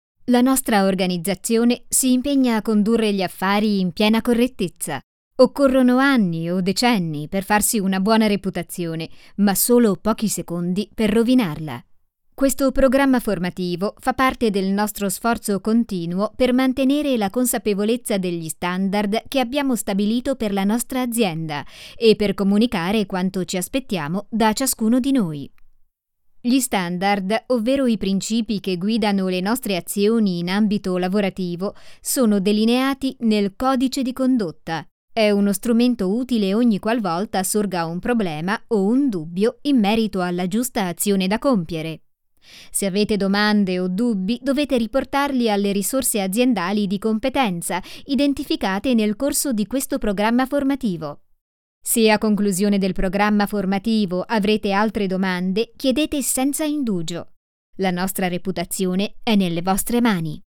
Sprecherin italienisch, bright, friendly, charming, versatile, young, professional
Sprechprobe: eLearning (Muttersprache):
female voice over artist italian, bright, friendly, charming, versatile, young, professional